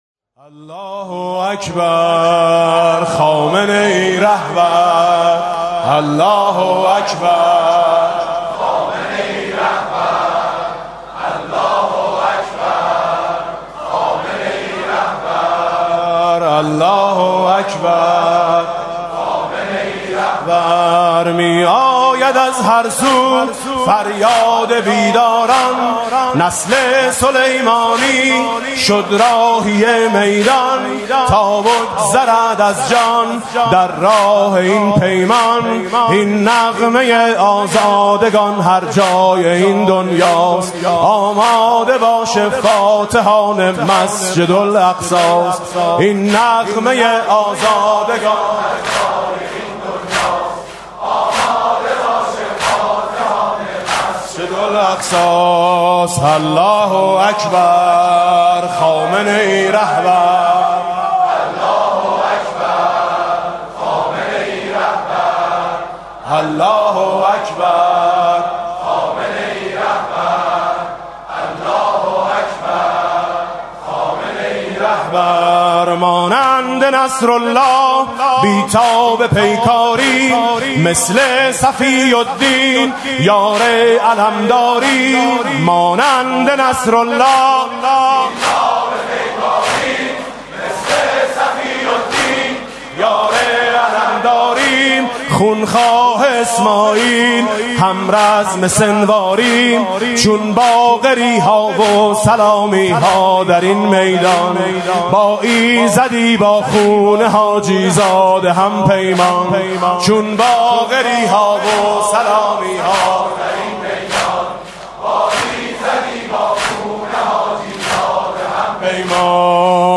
محفل عزاداری شب هفتم محرم هیأت آیین حسینی با سخنرانی حجت‌الاسلام رفیعی و بانوای میثم مطیعی در امامزاده قاضی الصابر (علیه‌السلام) برگزار شد.